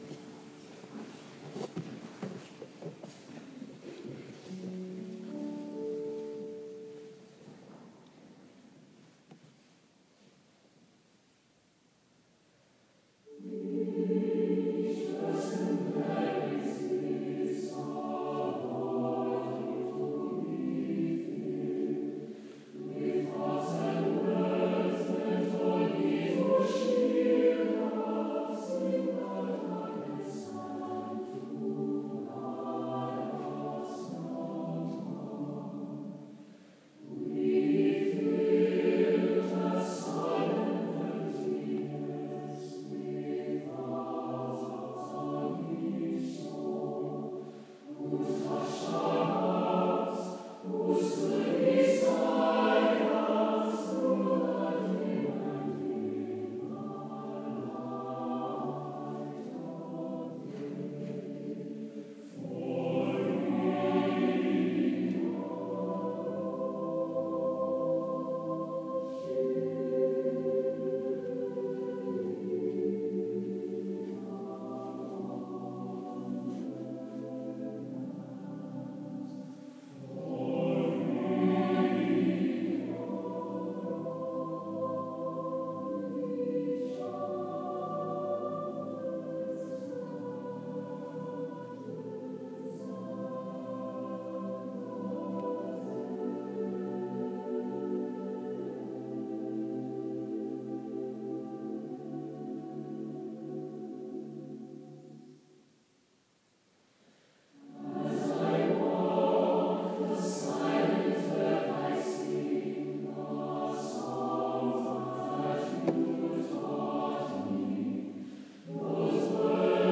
The choir is amazing.
All of the sound clips on this site are things they have sung this week.  Recorded on my iphone.